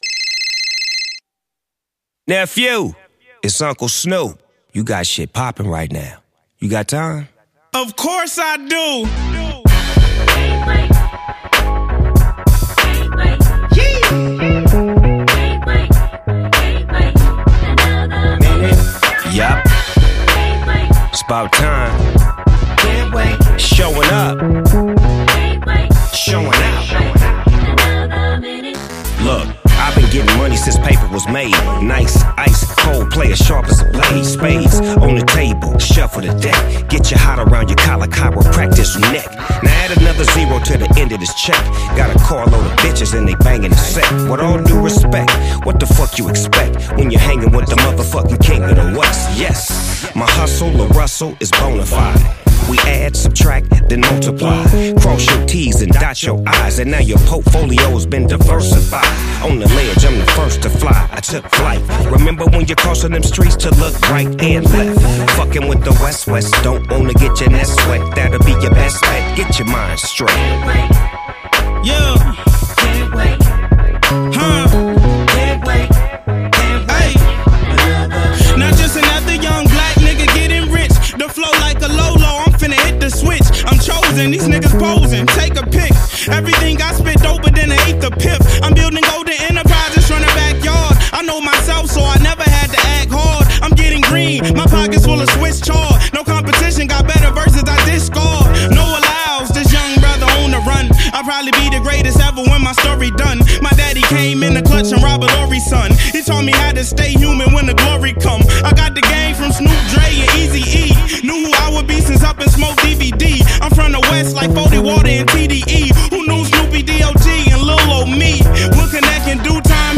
classic West Coast sound with smooth beats and cool lyrics
deep voice and relaxed flow
Fans of old-school hip-hop will love this album.